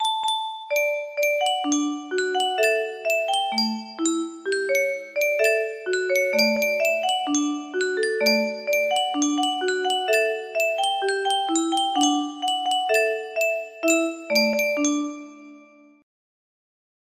Yunsheng Music Box - Unknown Tune 1660 music box melody
Full range 60